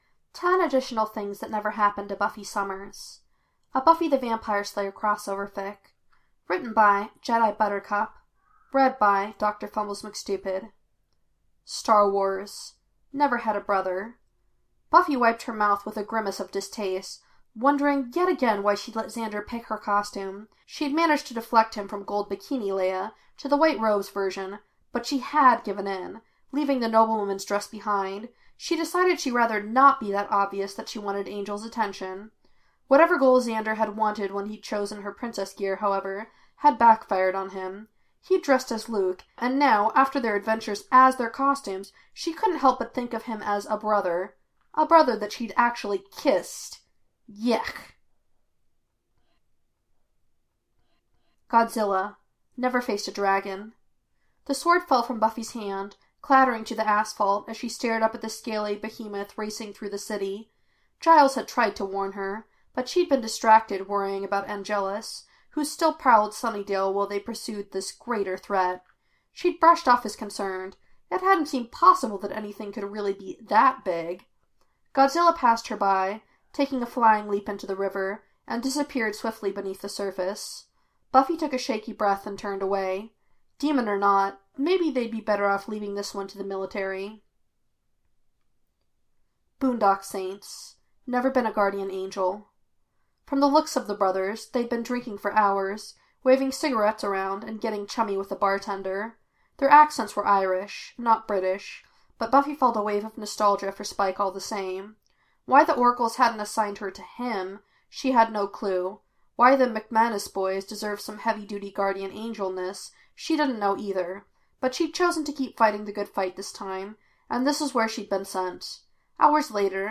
reader